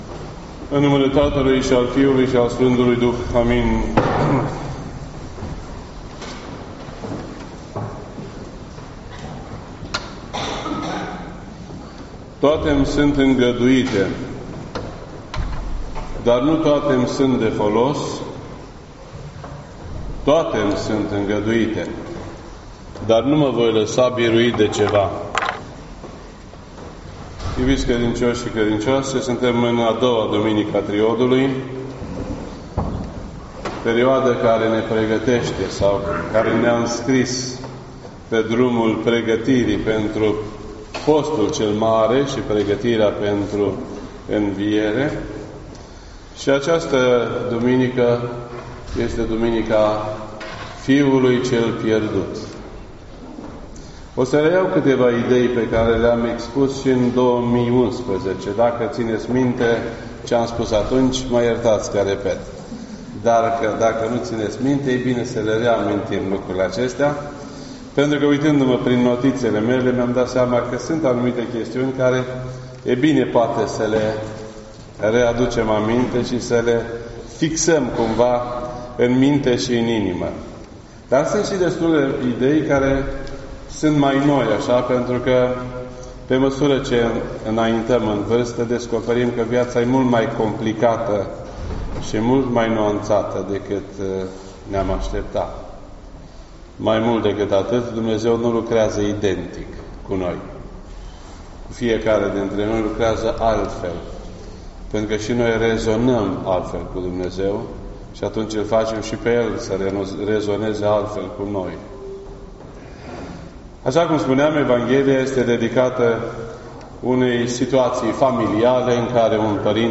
rusalii-34 This entry was posted on Sunday, February 24th, 2019 at 12:29 PM and is filed under Predici ortodoxe in format audio .